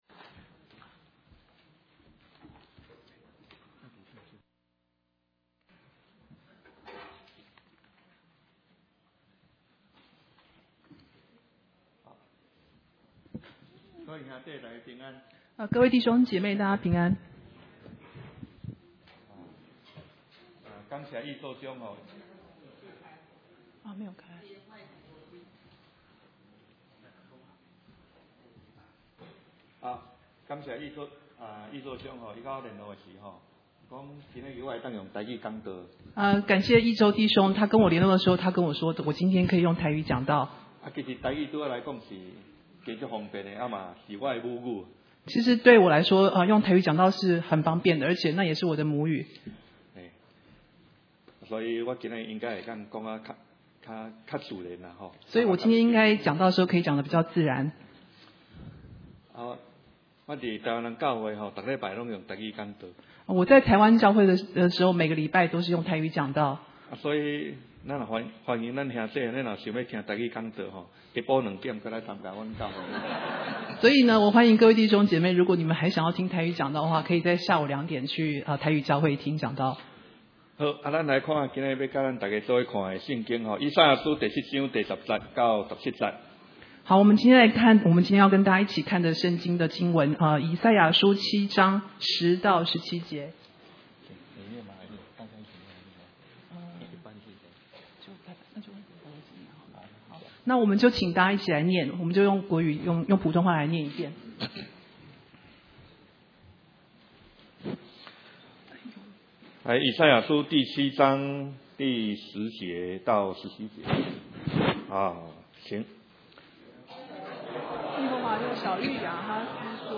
Mandarin Sermons